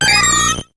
Cri de Zarbi dans Pokémon HOME.